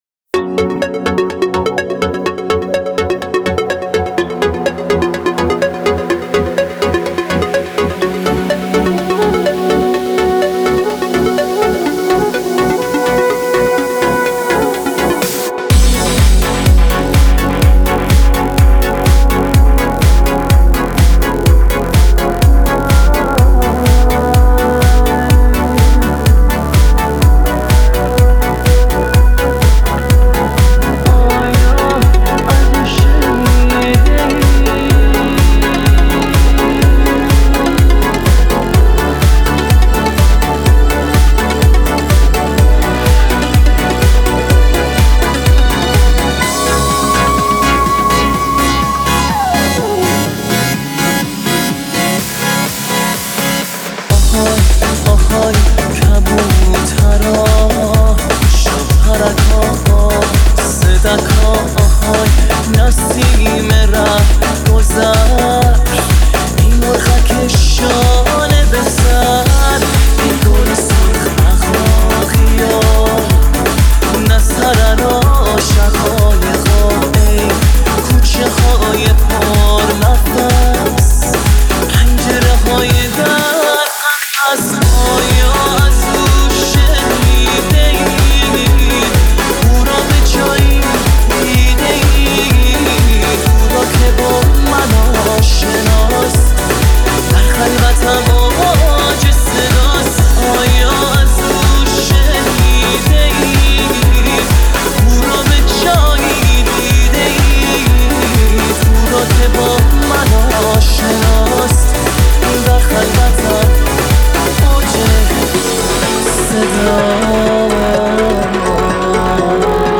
Progressive Remix